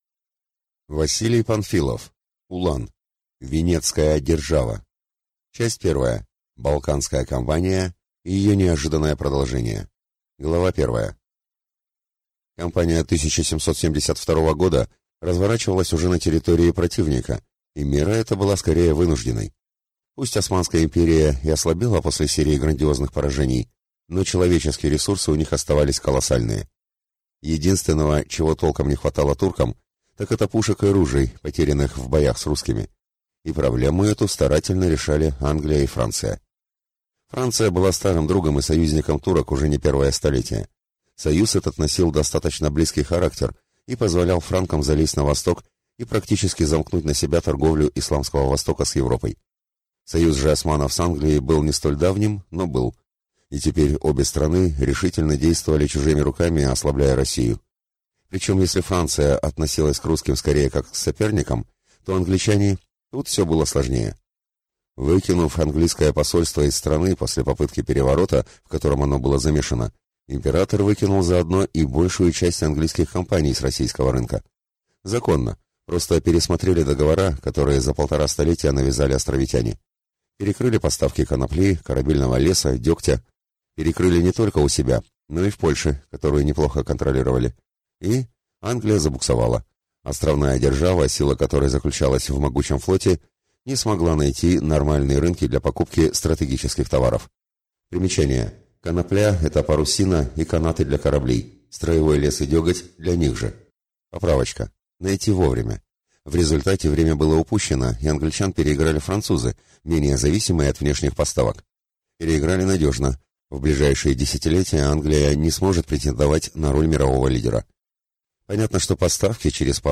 Aудиокнига Улан. Венедская держава